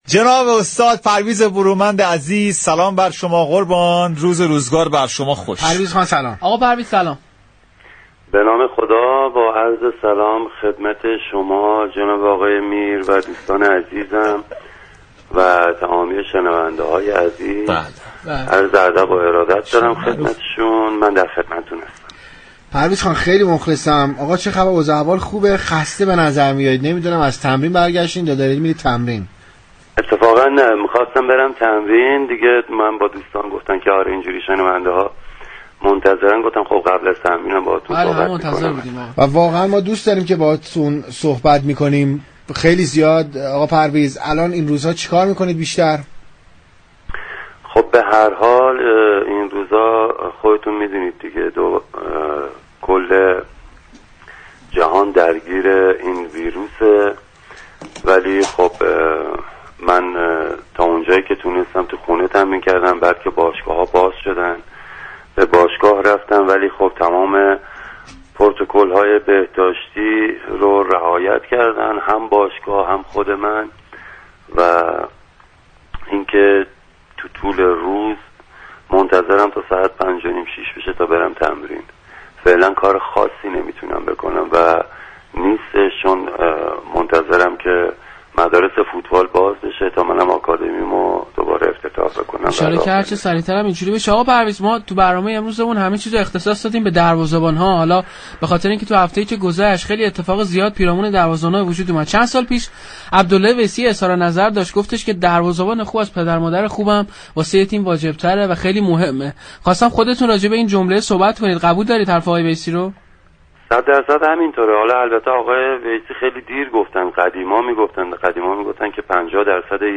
پرویز برومند، دروازه‌بان سابق استقلال در گفتگو با تهران ورزشی رادیو تهران درباره اهمیت پست دروازه‌بانی و عملكرد گلرهای تیم های ورزشی در روزهای اخیر گفت.